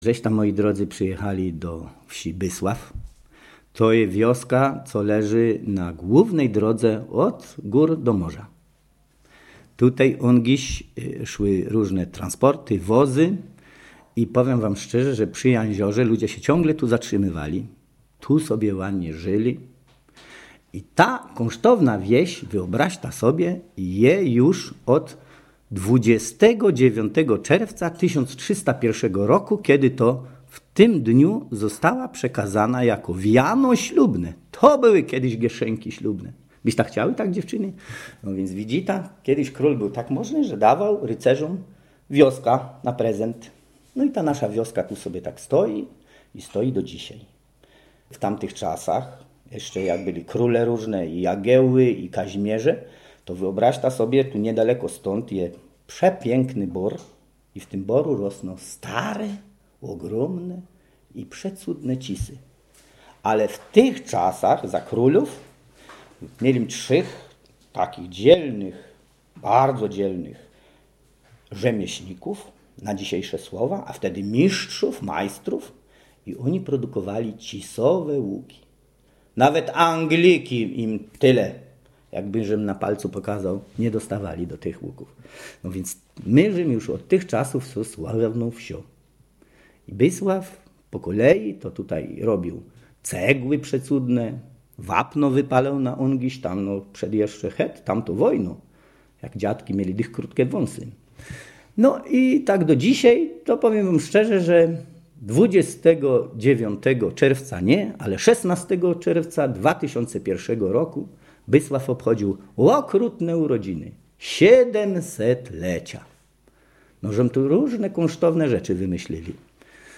Dialekt wielkopolski
gwara borowiacka (tucholska)
Tekst nagrano 27 października 2007 r. Wygłaszane teksty mają charakter stylizowanej gawędy.